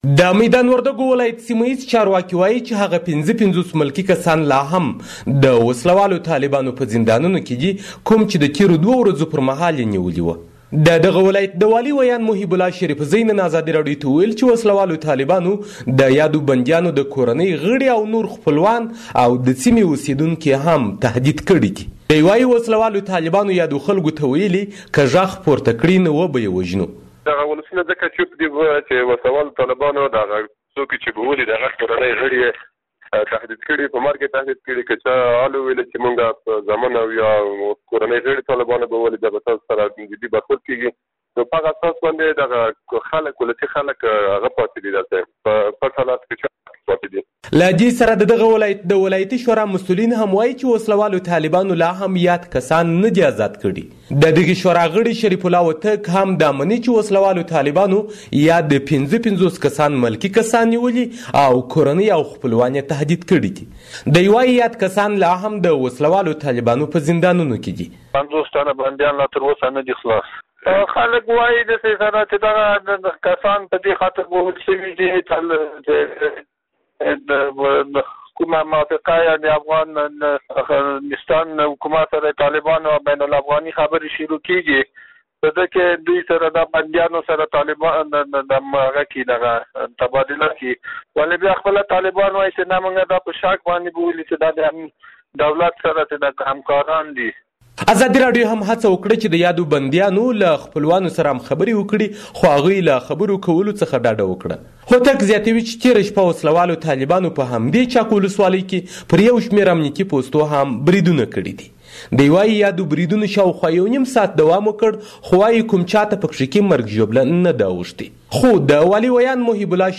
د بندیانو په اړه راپور